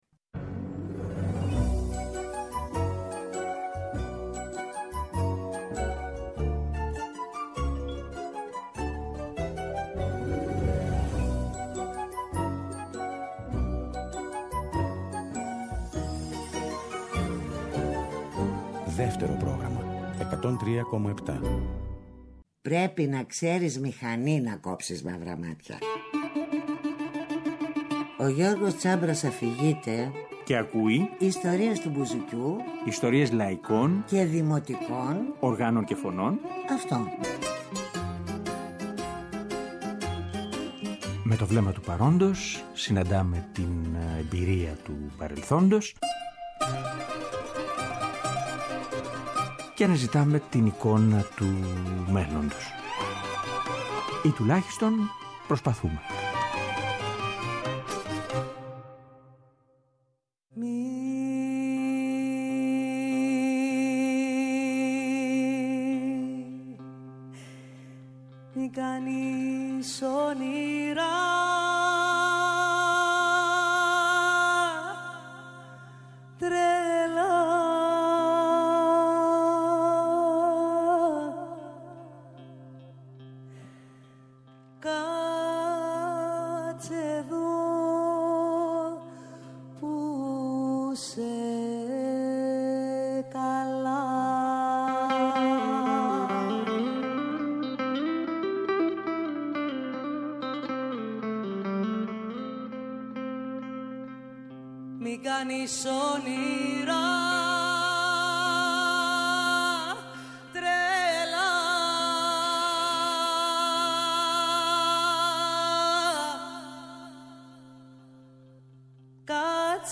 Ιστορίες και τραγούδια με στίχους Ευτυχίας Παπαγιαννοπούλου